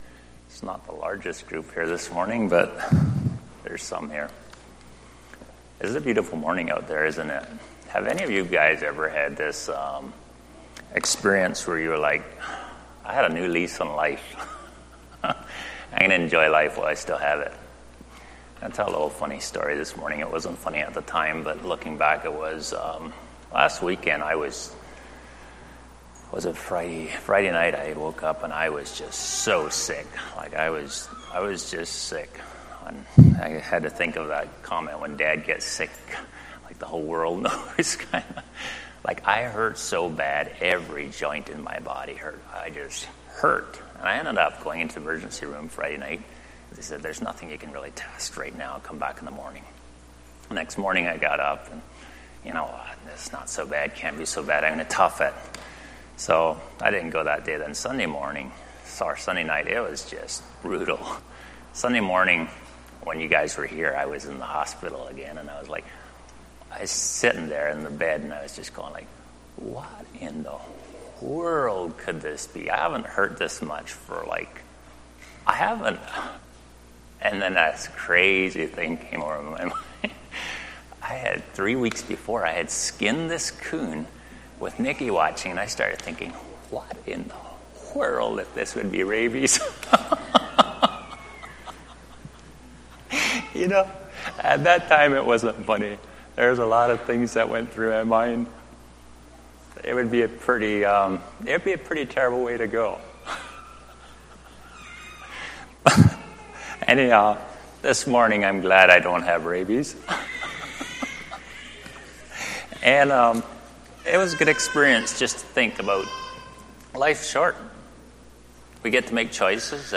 Sunday Morning Bible Study Service Type: Sunday Morning